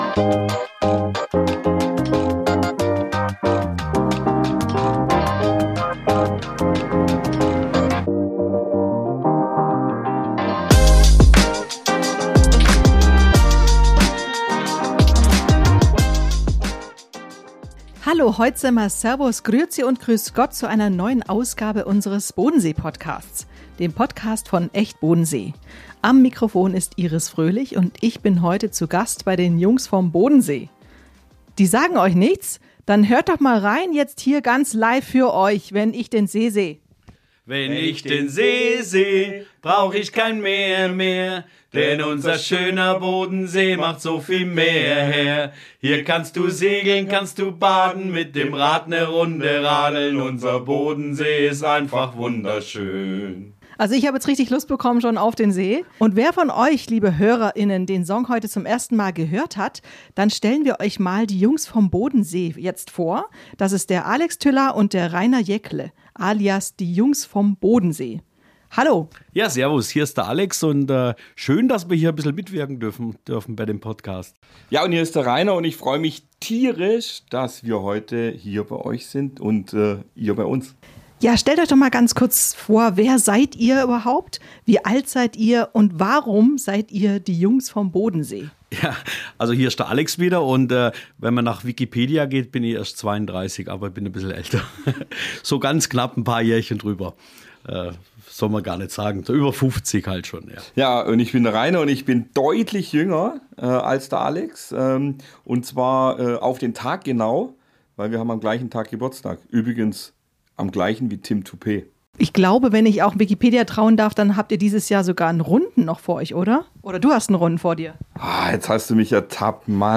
Wie es damals anfing und was sie derzeit planen, darüber mehr im Interview!